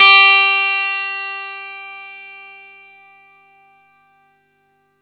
R12NOTE G +2.wav